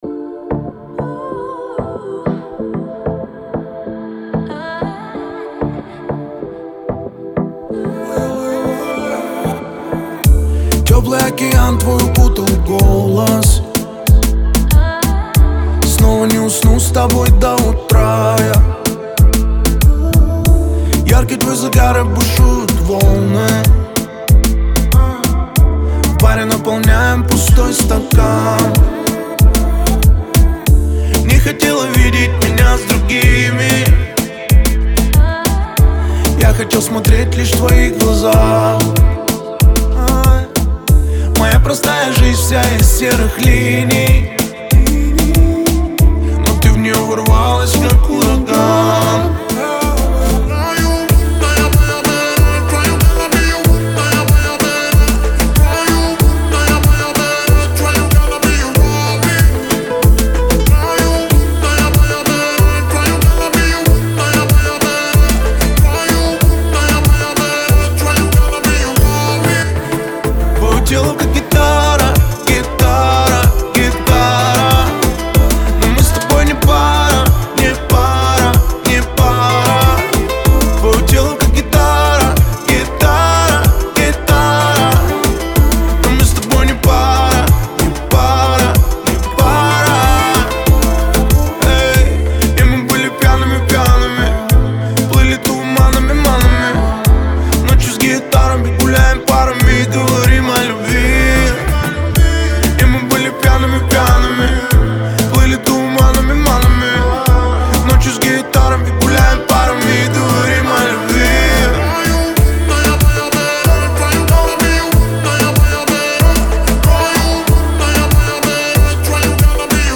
яркая и энергичная песня